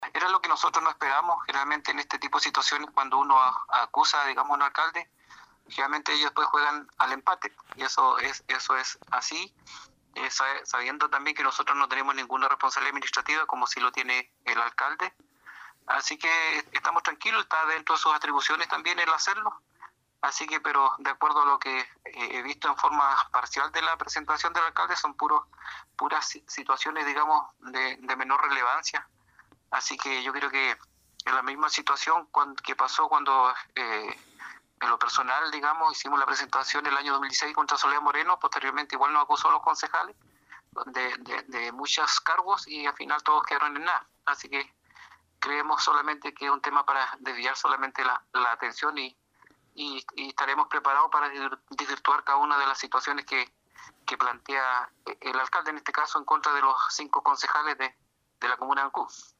Estos son algunos de los puntos que Carlos Gómez pide al tribunal electoral regional observe para removerlos de sus cargos y sobre los que se defendió el concejal Alex Muñoz.
11-CONCEJAL-ALEX-MUNOZ.mp3